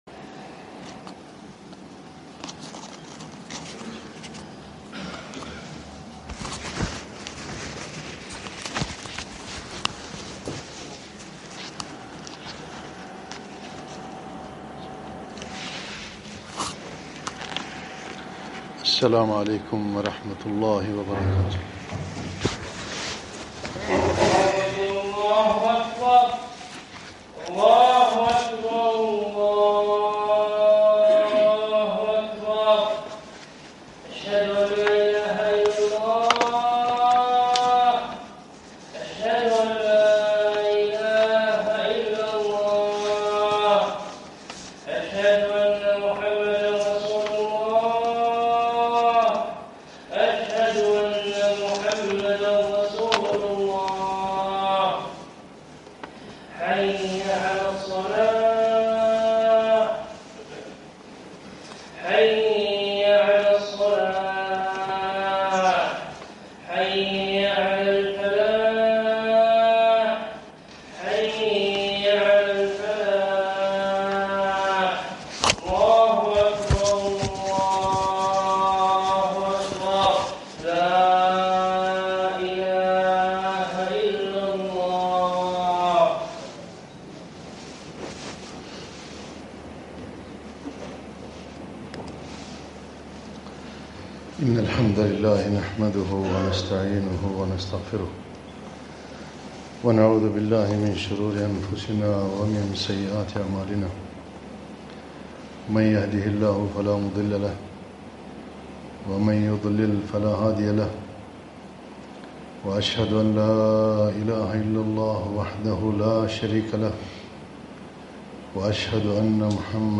خطبة - الحكمة في تكرار الفاتحة في الصلوات